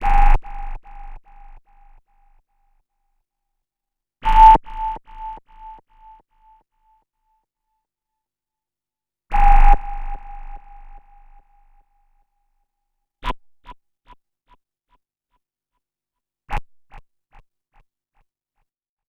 59-SONAR  -R.wav